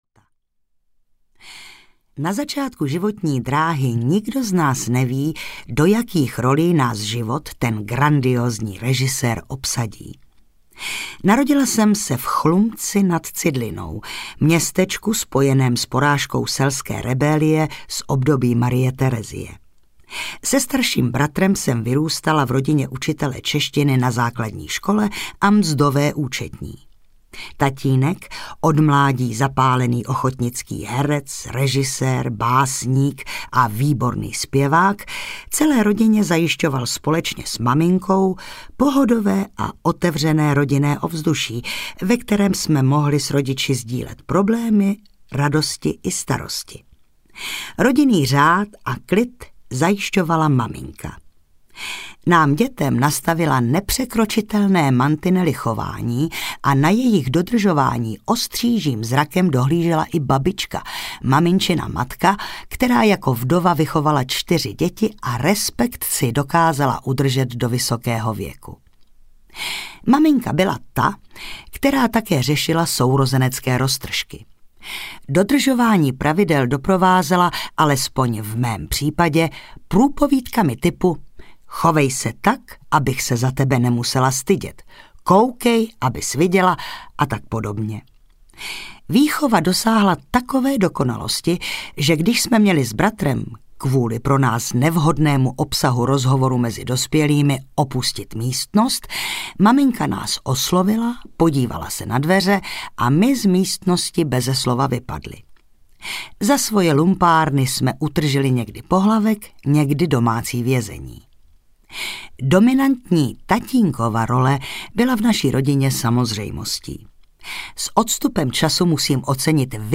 Myšlenky zločince v české kotlině audiokniha
Ukázka z knihy